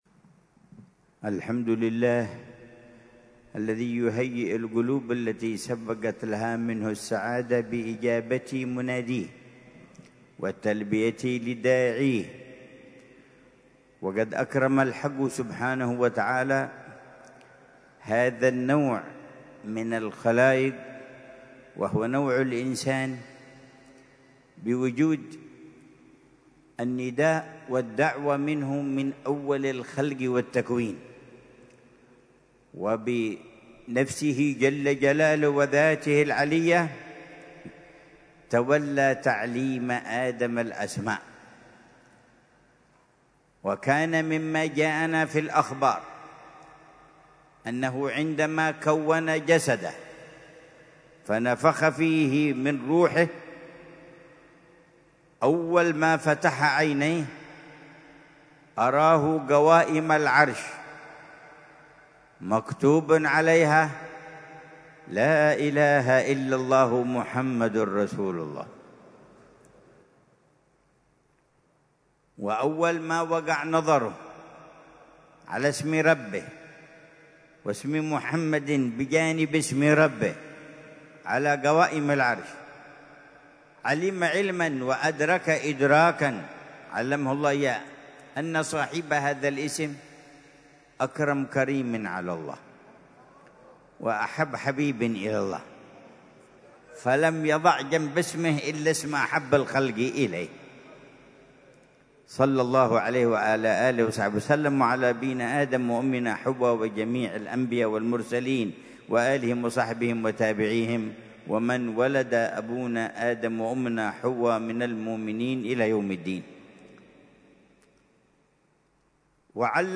محاضرة العلامة الحبيب عمر بن محمد بن حفيظ ضمن سلسلة إرشادات السلوك، ليلة الجمعة 24 ذو الحجة 1446هـ في دار المصطفى بتريم، بعنوان: